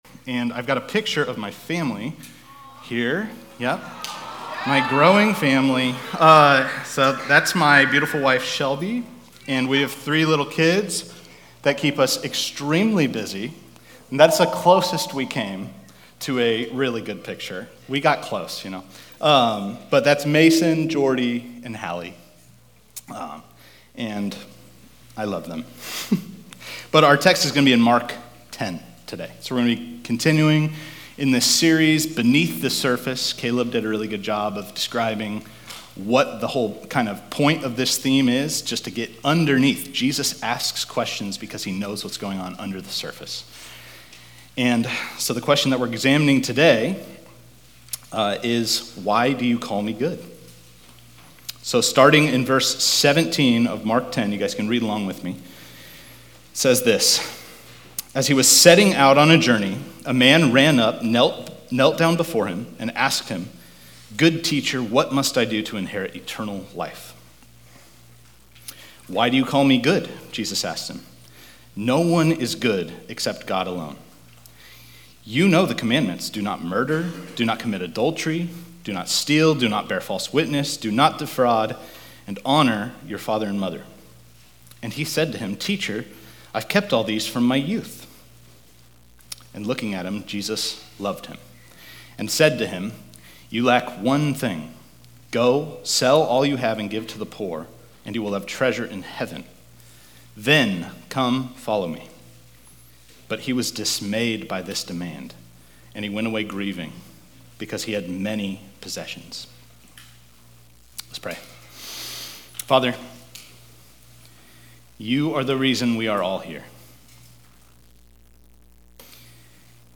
Fall Conference 2025 Beneath the Surface